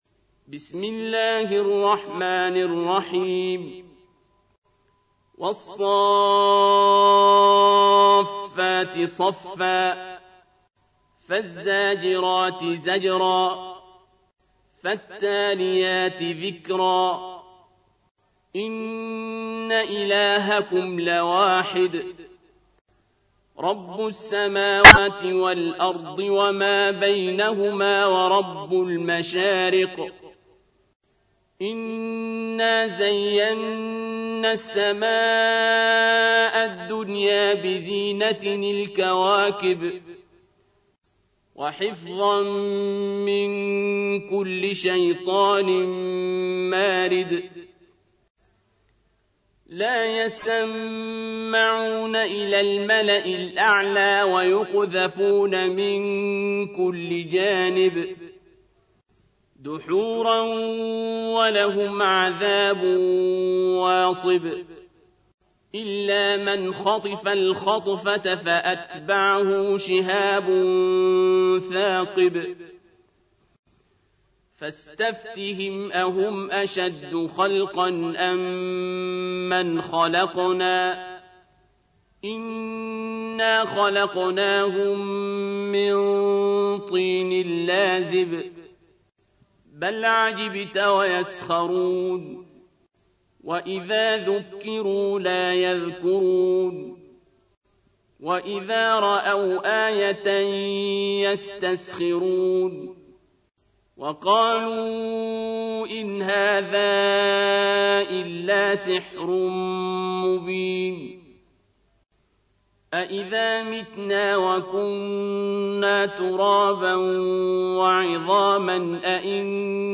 تحميل : 37. سورة الصافات / القارئ عبد الباسط عبد الصمد / القرآن الكريم / موقع يا حسين